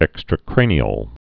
(ĕkstrə-krānē-əl)